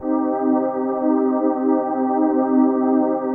PHASEPAD33-LR.wav